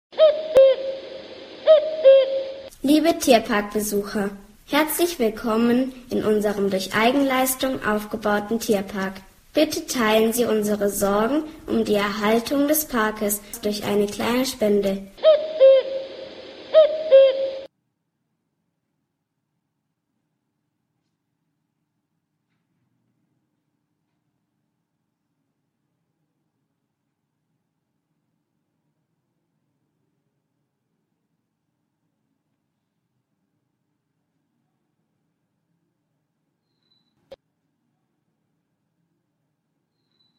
KUCKUCK! KUCKUCK!
Liebe Tierparkbesucher ...